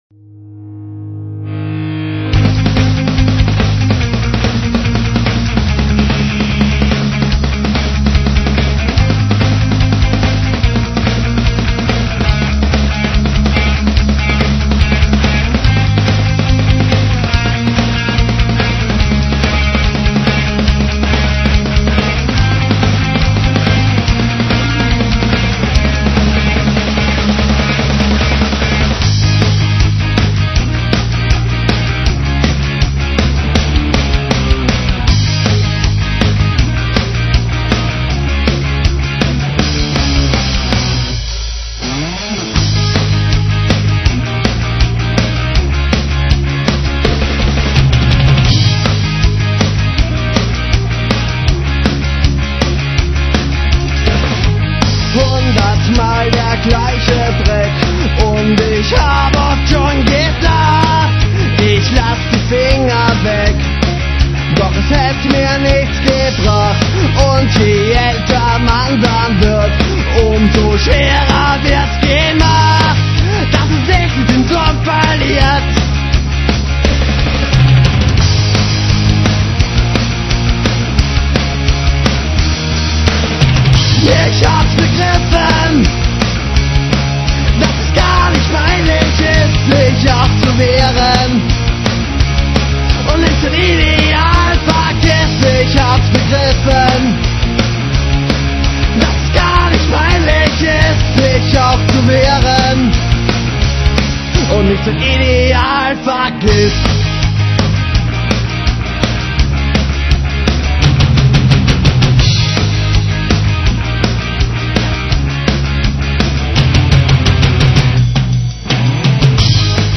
Gitarre, Bass, Schlagzeug.
Und lautes Geschrei.
Schnell, mitreißend, kultverdächtig.